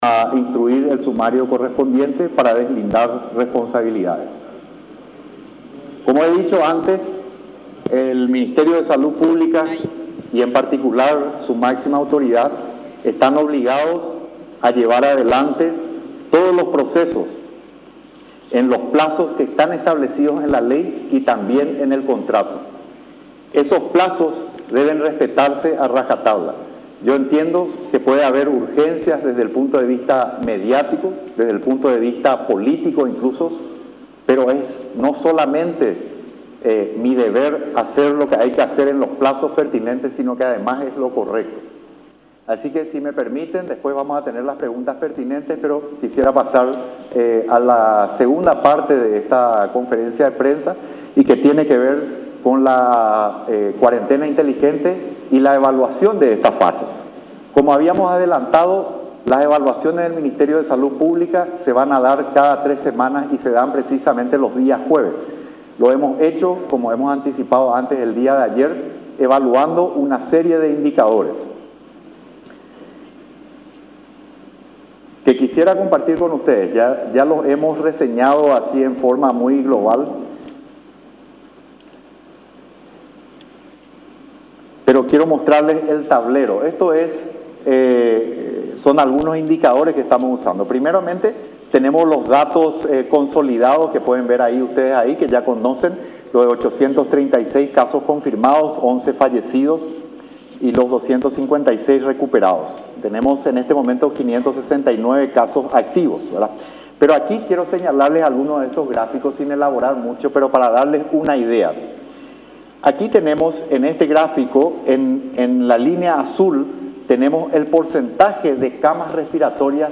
12-CONFERENCIA-MAZZOLENI.mp3